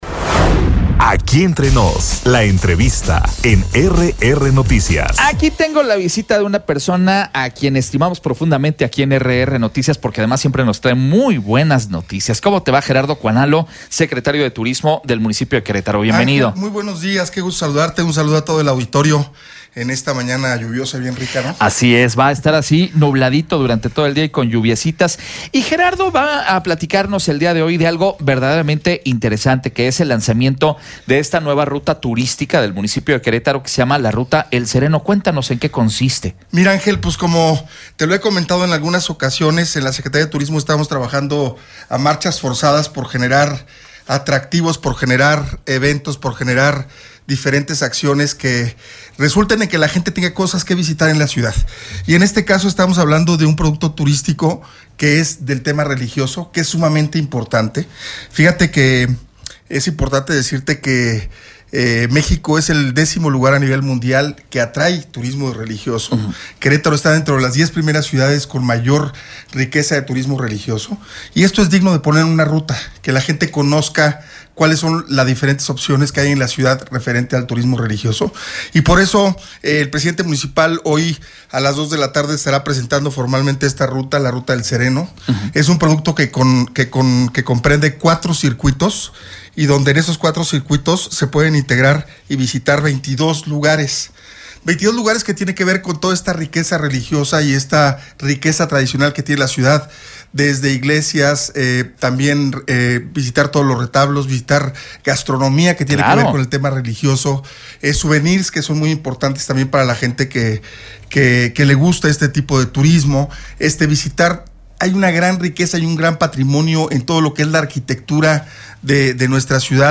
Presentan ruta turística de El Sereno. Entrevista